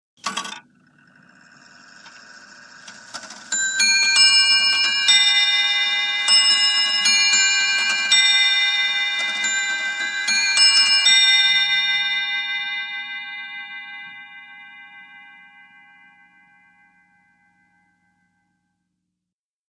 CLOCKS ANTIQUE CLOCK: INT: Antique clock bell chimes one hour.